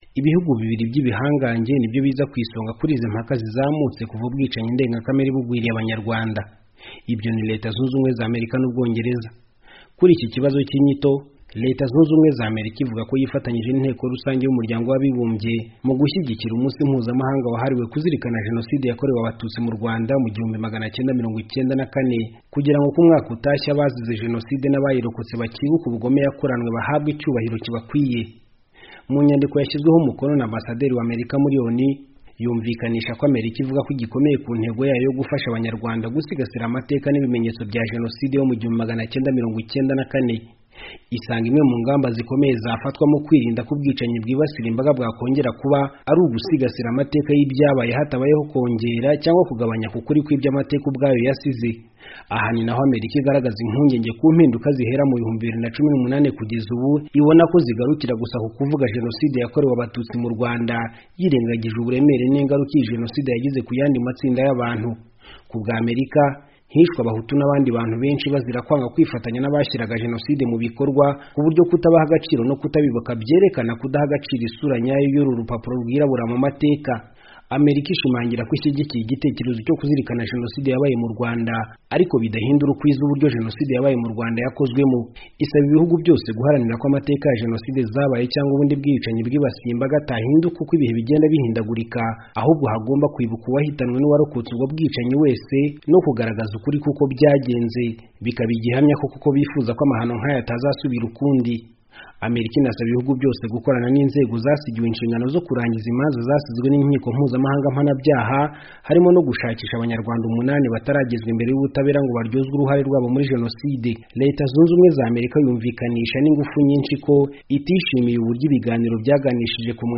Inkuru